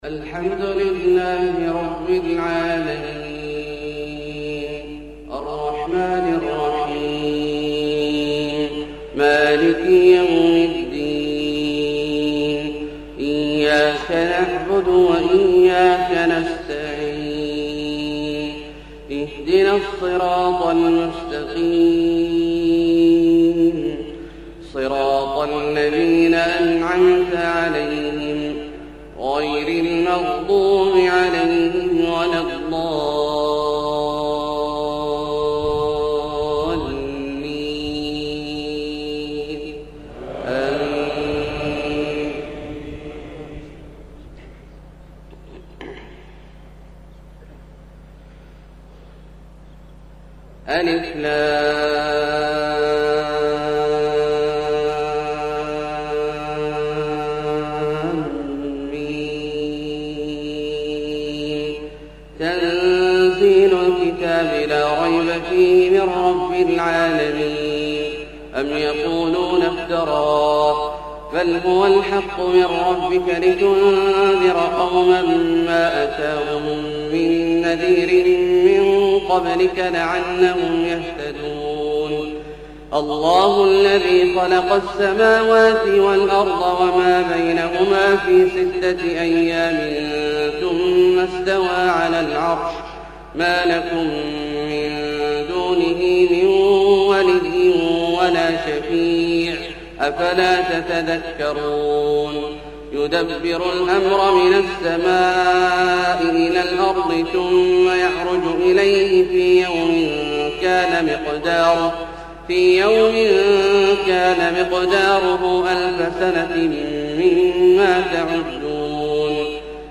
فجر 5-6-1430 سورتي السجدة و الانسان > ١٤٣٠ هـ > الفروض - تلاوات عبدالله الجهني